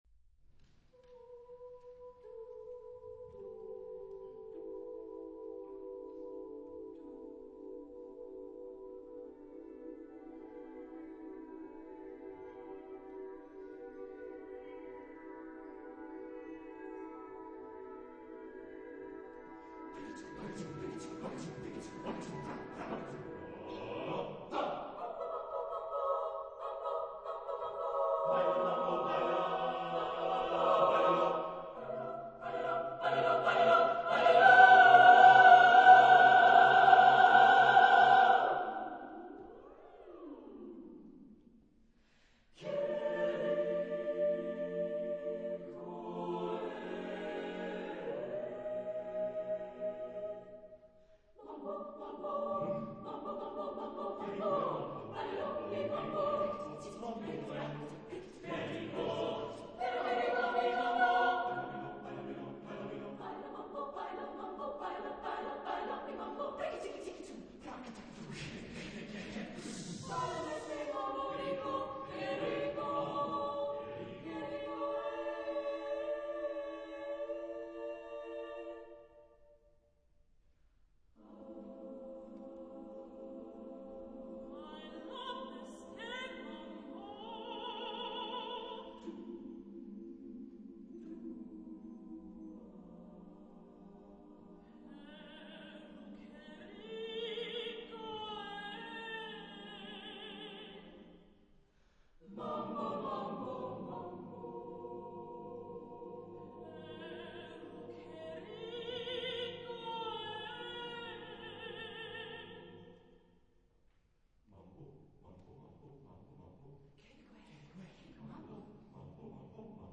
Genre-Style-Forme : Mambo ; ballet ; Profane
Type de choeur : SSSAAATTBB  (10 voix mixtes )
Tonalité : libre